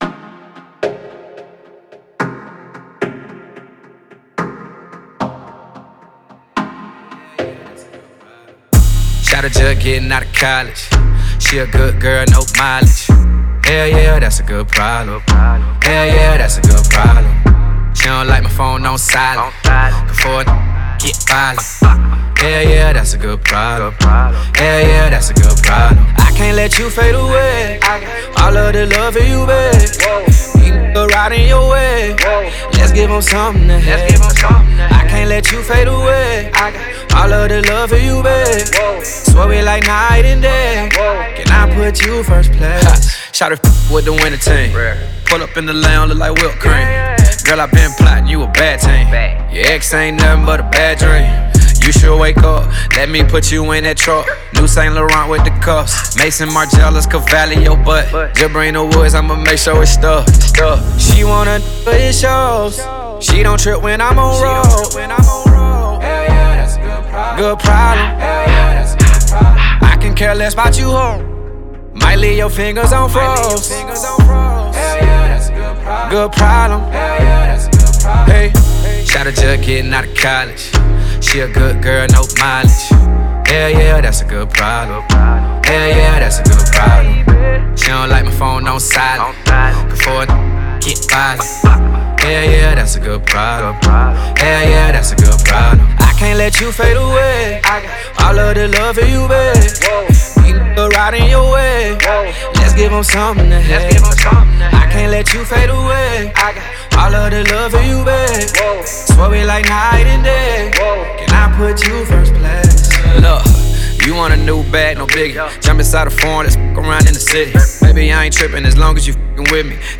HIP-HOP/RAP